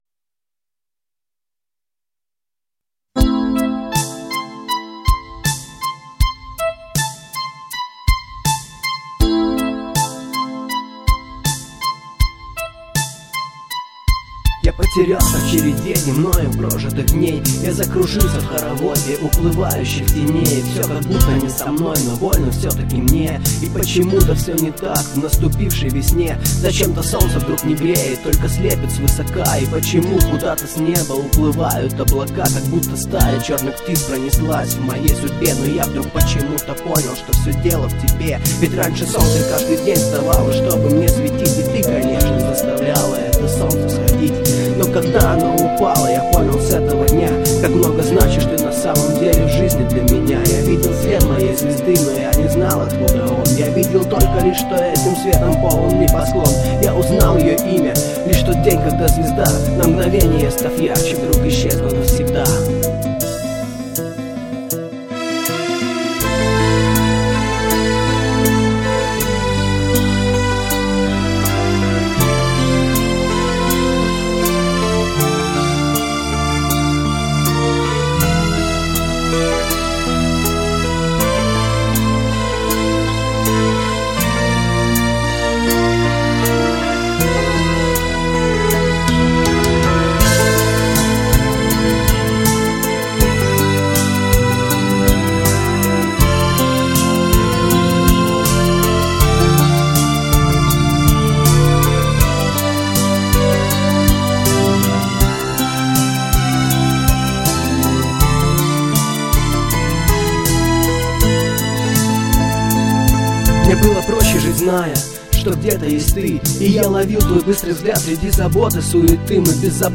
Клавиши, перкуссия, вокал